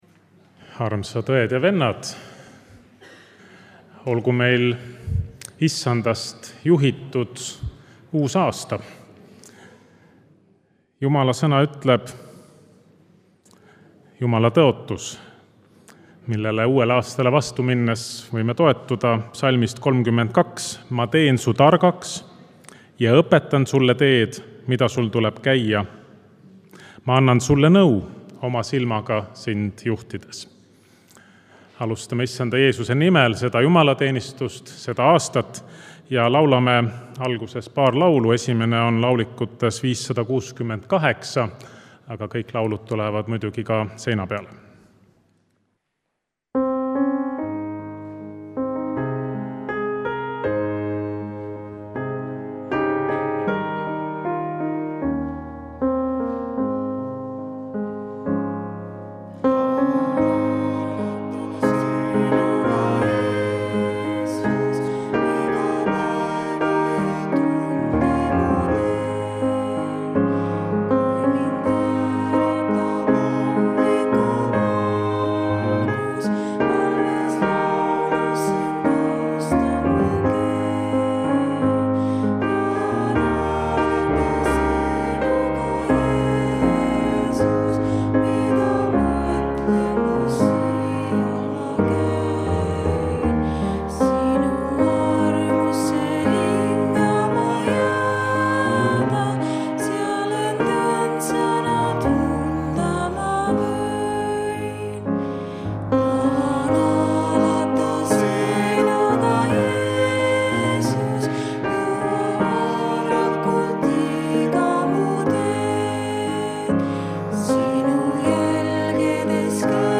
Jutlus